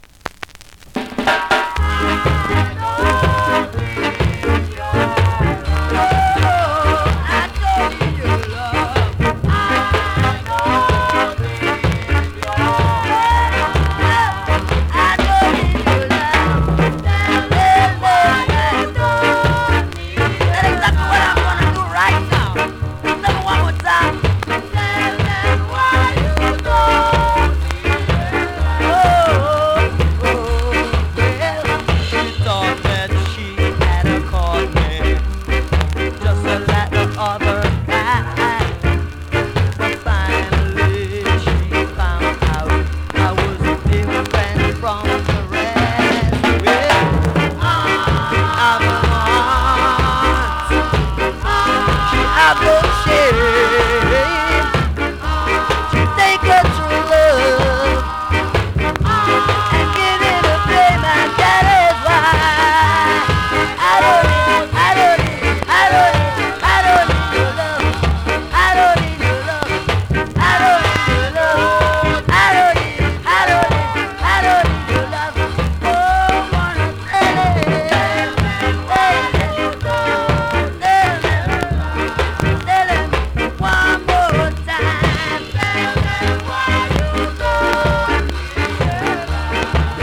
スリキズ、ノイズそこそこあります。